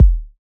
SOUTHSIDE_kick_deep_dirt.wav